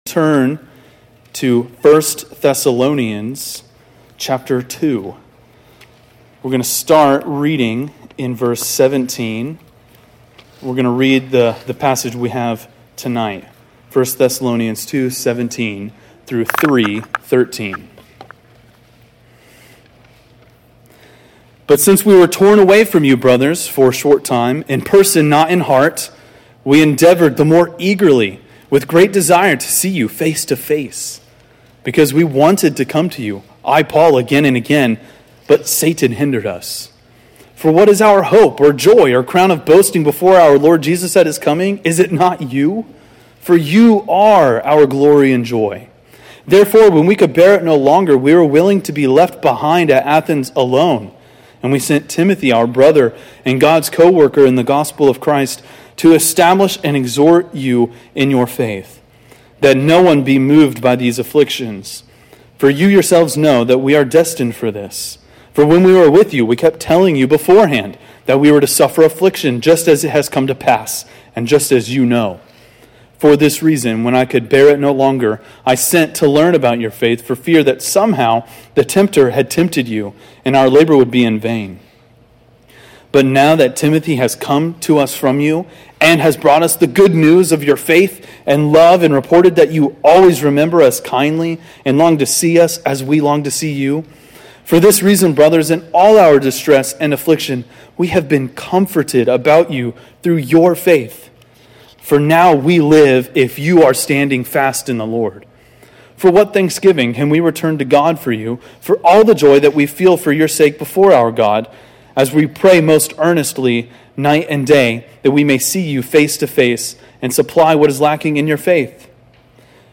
preaches through 1 Thessalonians 2:17-3:13.